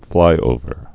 (flīōvər)